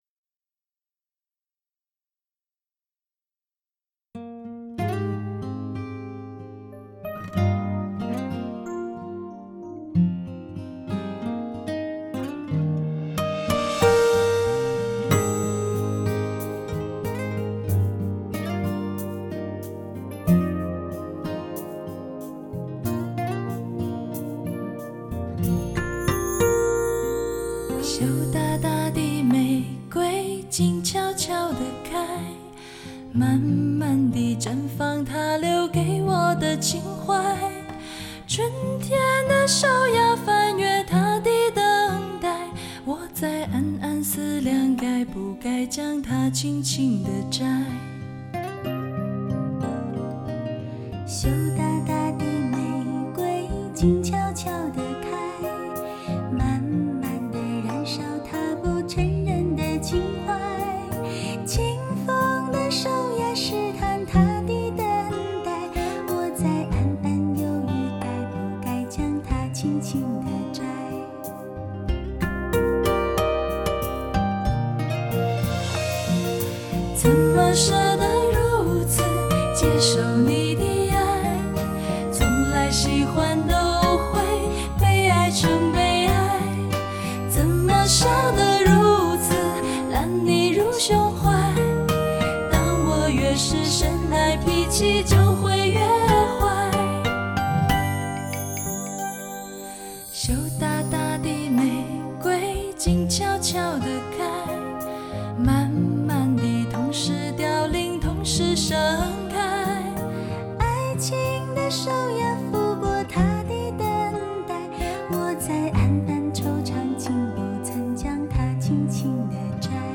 带来超乎想象震撼性6.1环绕声体验
女子三重唱
还用到顶级真空管对音质进行品质处理